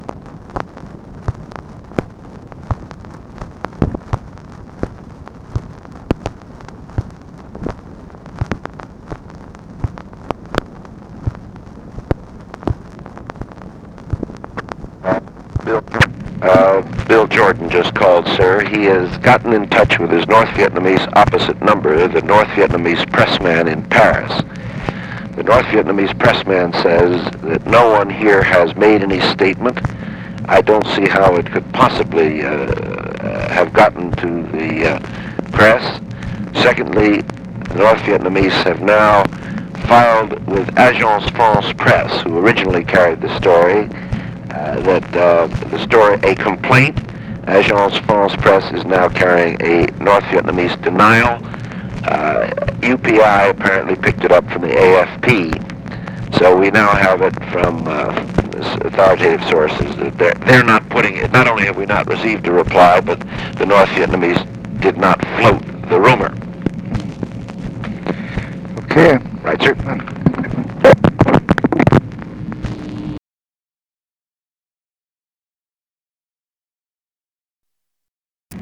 Conversation with WALT ROSTOW, October 19, 1968
Secret White House Tapes